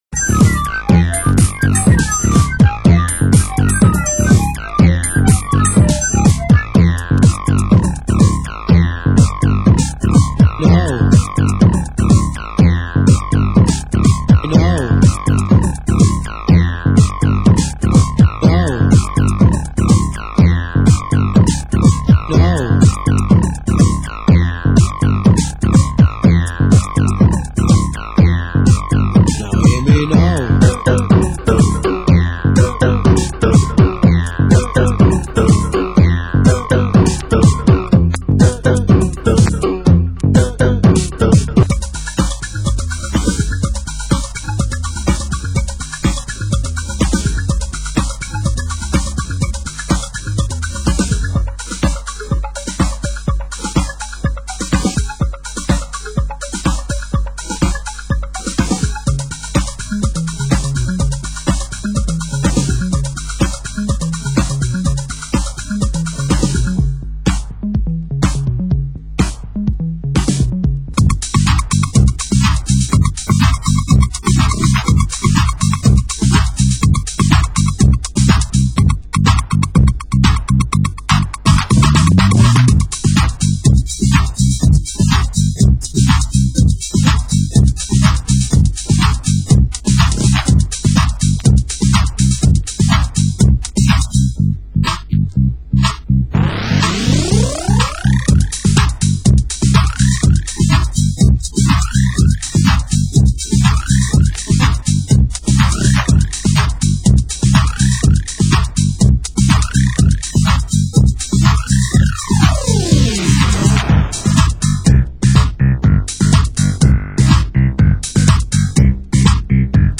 Format: Vinyl 12 Inch
Genre: Hardcore